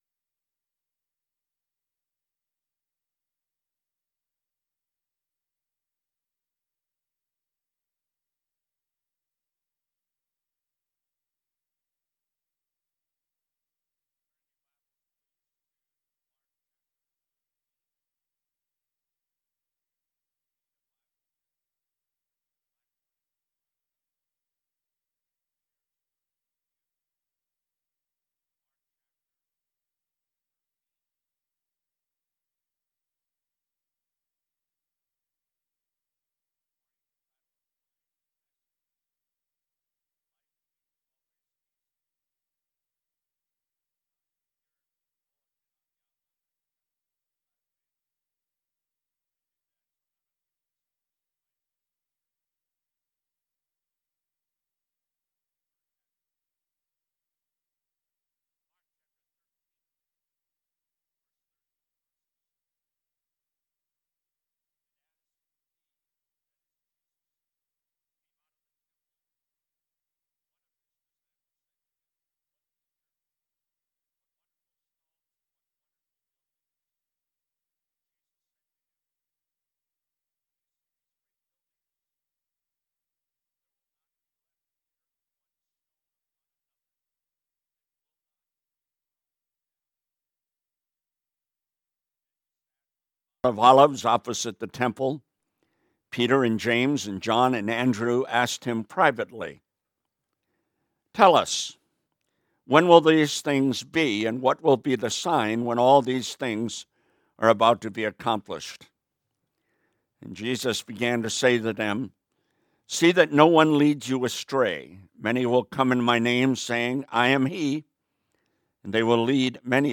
Sermon: “Where Should We Serve"